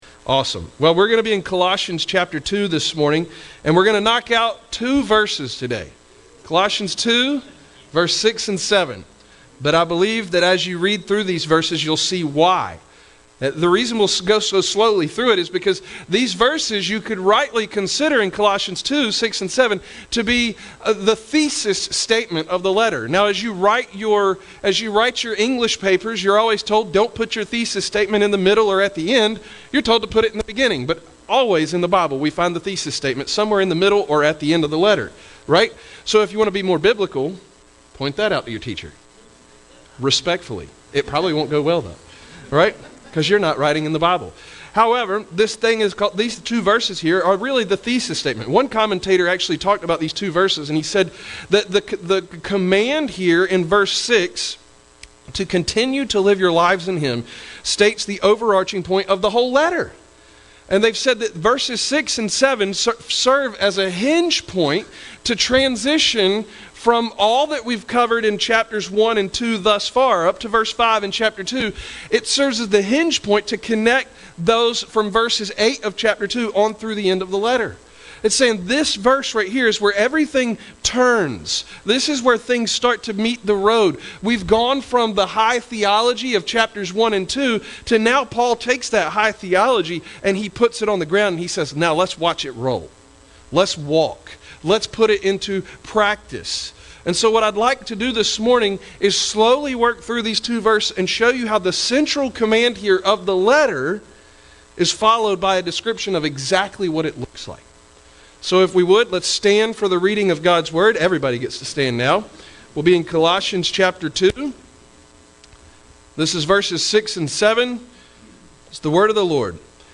teaches from Colossians 2:6-7